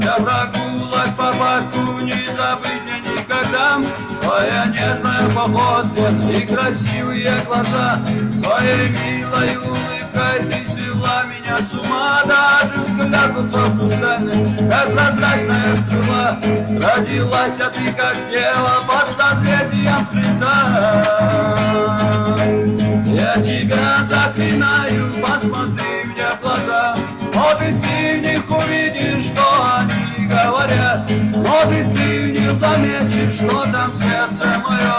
Жанр: Шансон / Русские